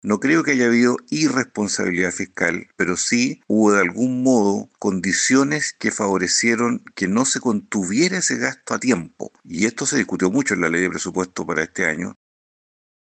Desde la otra vereda política, el senador del Partido Socialista, Juan Luis Castro, dijo que -a su juicio- la situación no obedece necesariamente a un actuar irresponsable en materia fiscal, sino a factores que también deben analizarse en su contexto económico.